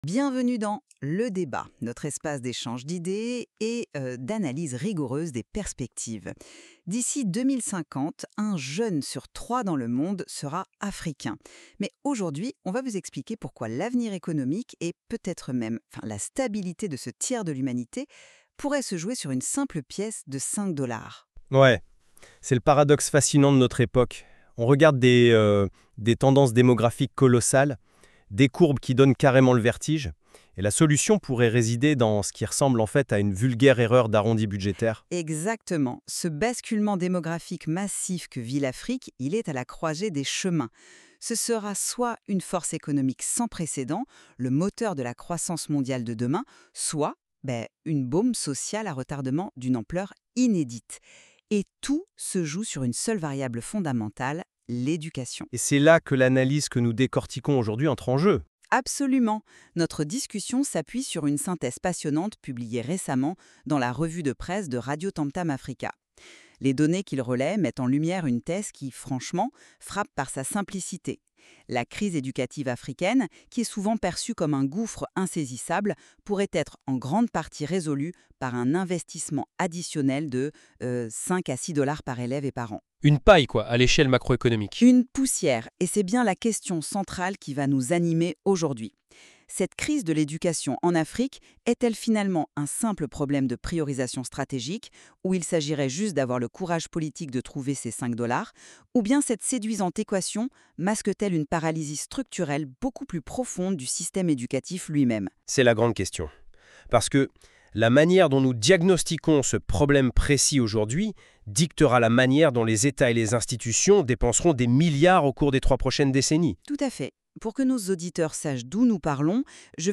Bienvenue dans la revue de presse de l’actualité africaine sur RADIOTAMTAM AFRICA…La voix des peuples.